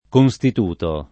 constituto [ kon S tit 2 to ]